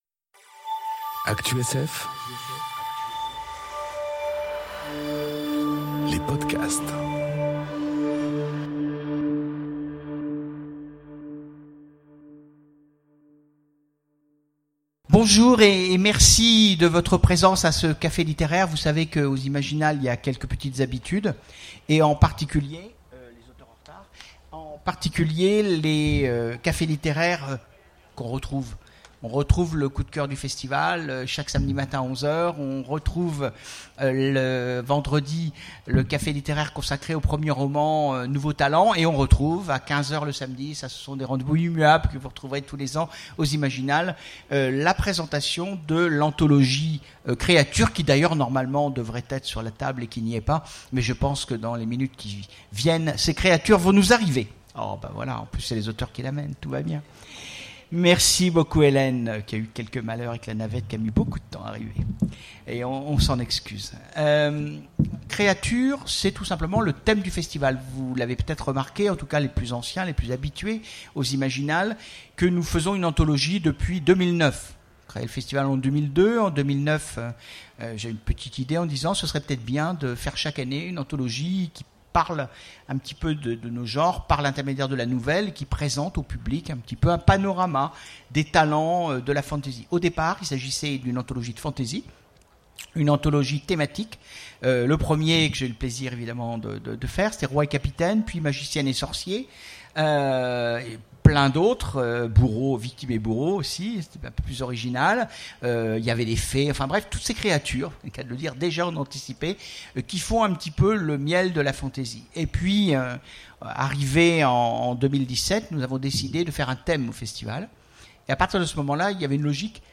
Conférence Créatures : l'anthologie du festival ! enregistrée aux Imaginales 2018